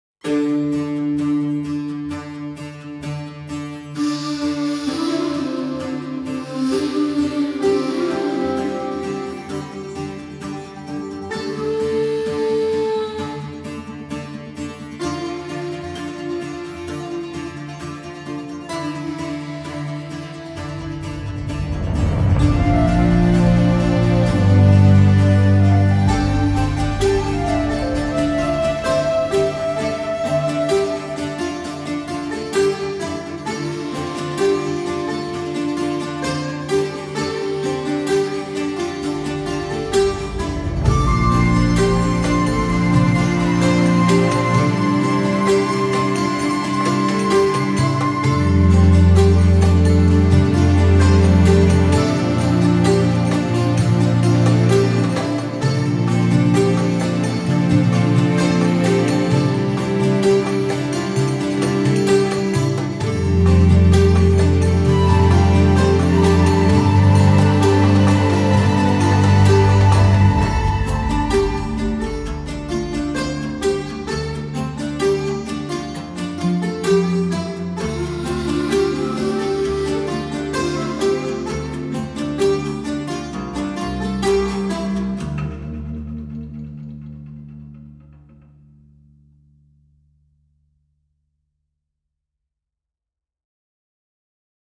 Middle Eastern strings and winds
Virtuoso playing meets deep tradition